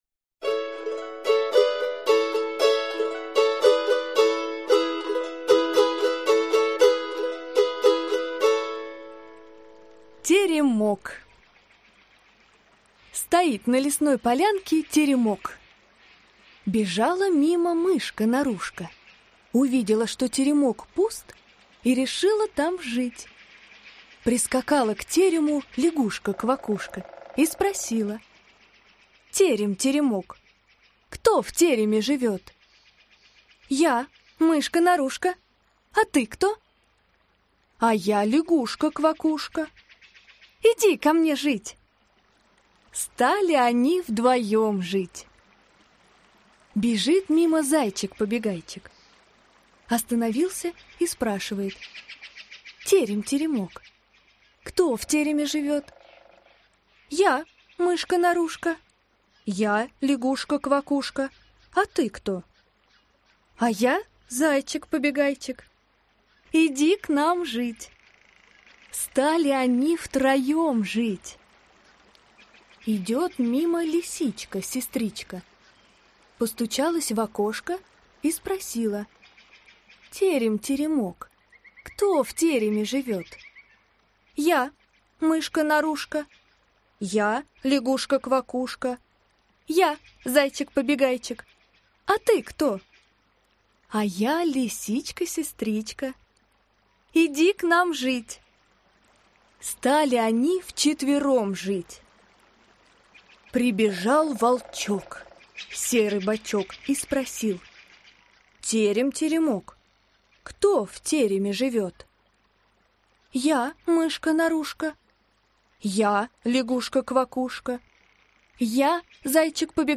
Аудиокнига Для самых маленьких. Сказки-малютки | Библиотека аудиокниг